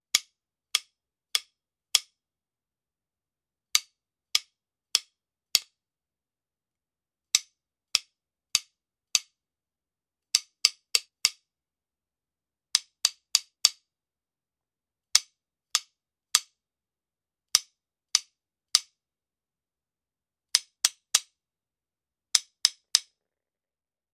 Звуки барабанных палочек
Звук отсчета барабанными палочками перед игрой на музыкальном инструменте серия звуков для монтажа